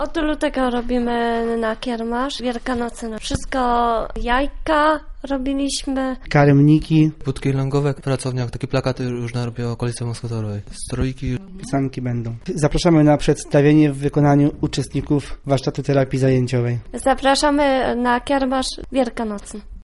Ponadto będzie możliwość zakupienia różnego rodzaju ozdób świątecznych, mówią uczestnicy warsztatów, którzy własnoręcznie je przygotowują.